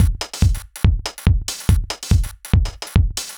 Index of /musicradar/uk-garage-samples/142bpm Lines n Loops/Beats
GA_BeatA142-06.wav